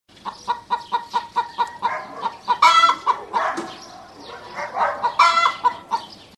Звуки курятника
На этой странице собраны разнообразные звуки курятника: от кудахтанья кур до петушиных криков на рассвете.